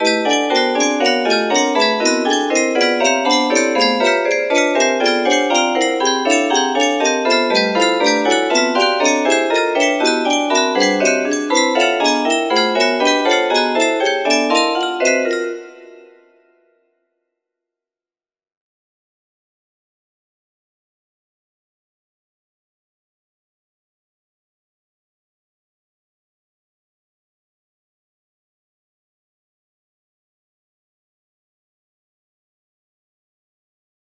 P22 music box melody